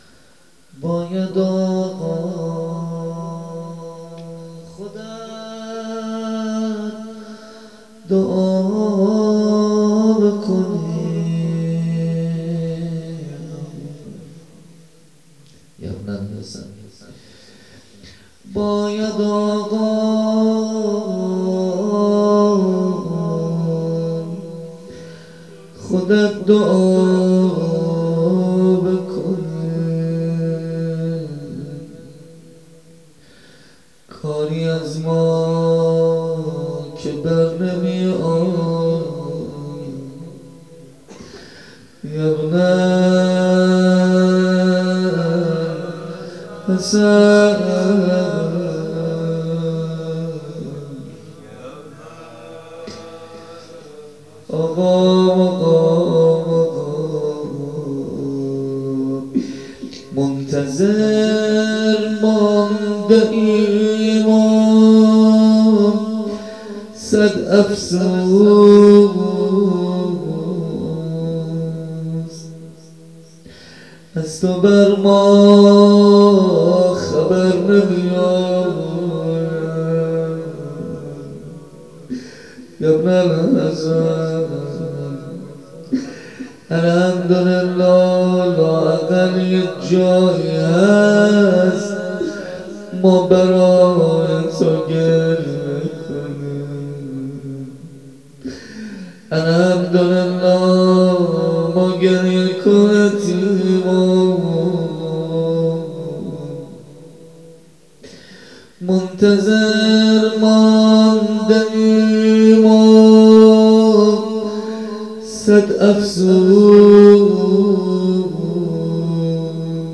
روضه هفتگی